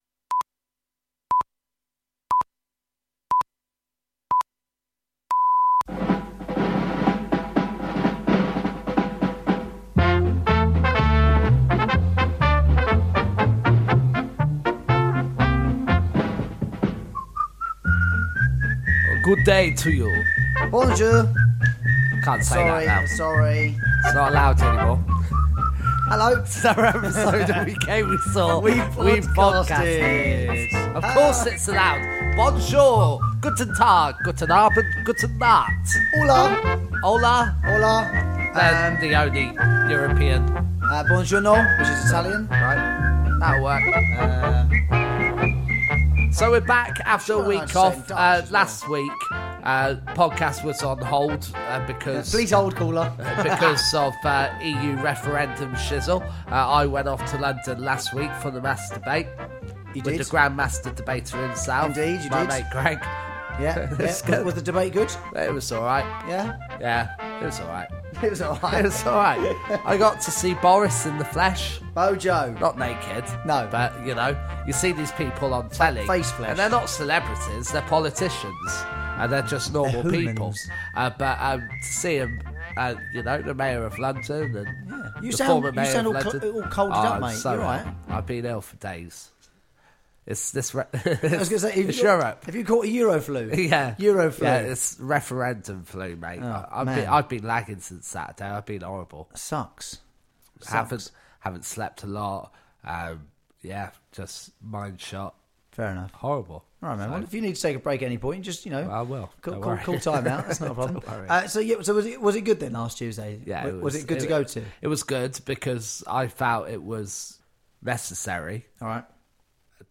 And maybe start a public sing-song with us as we sing our favourite european song for potentially the last time EVER!!* Enjoy x